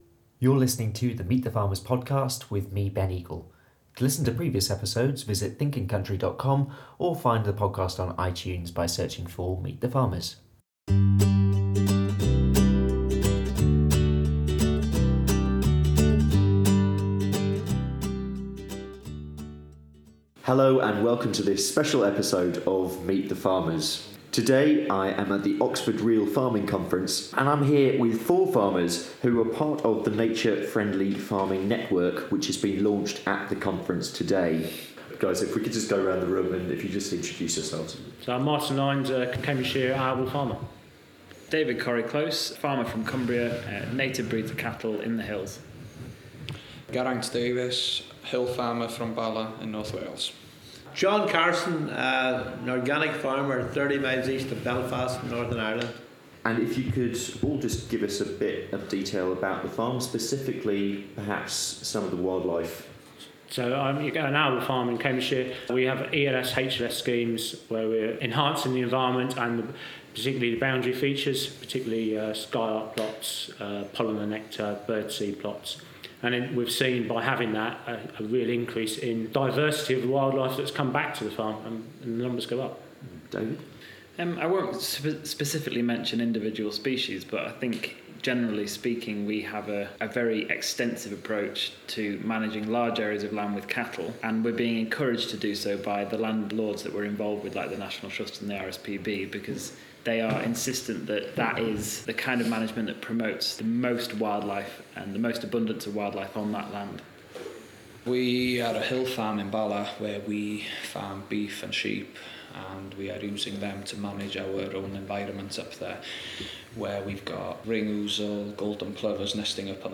Last month I spoke to four farmers who are part of the Nature Friendly Farming Network which was launched at the Oxford Real Farming Conference.